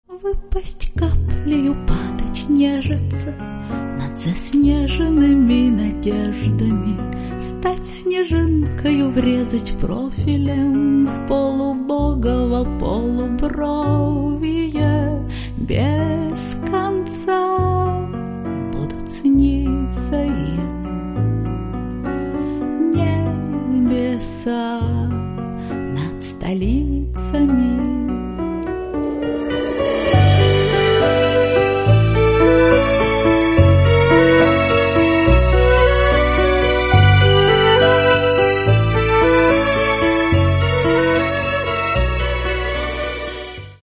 Catalogue -> Rock & Alternative -> Lyrical Underground